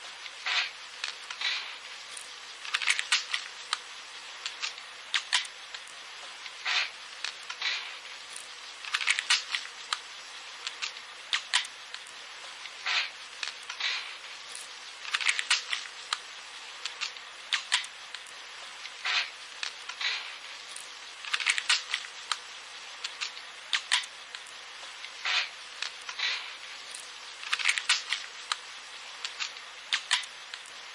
卡式录音带5分钟静音底噪
描述：紧凑音频盒带5分钟静音底噪。似乎是一个很好的资源来伪造旧录音。一个很好的背景噪音和过滤材料。录制信号路径： RSB505→Beheringer Xenyx 805→Zoom H2，记录在48kHz / 24位。 使用Ardor 2编辑并转换为FLAC。
标签： 磁带 静电 紧凑的带盒 磁带录音机 噪声 卡式录音带 底噪
声道立体声